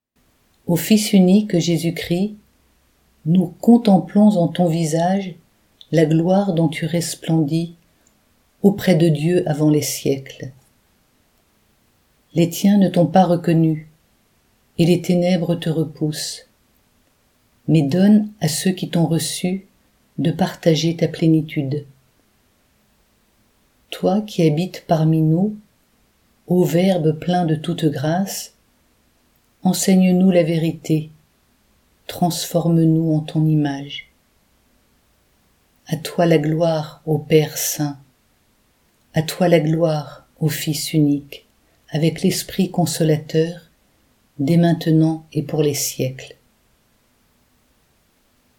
Hymne-O-Fils-unique-Jesus-Christ.mp3